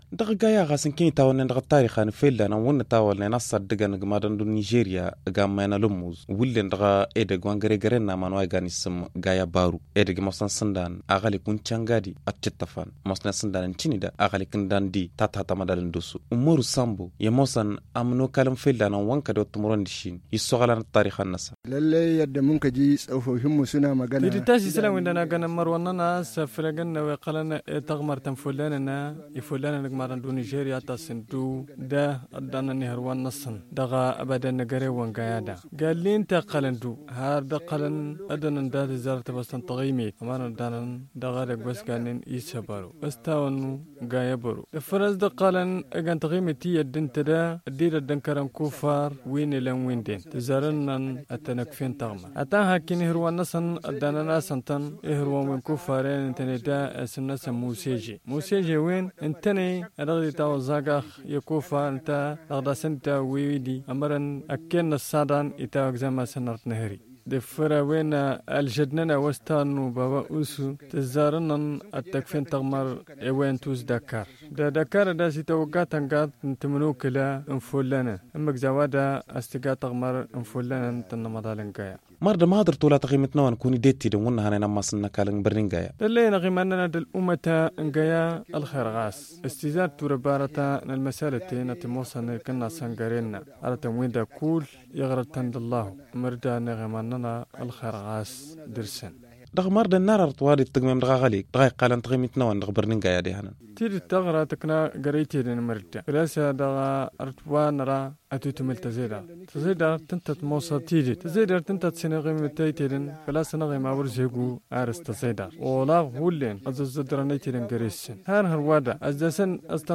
C’est dans un entretien accordé à Gaya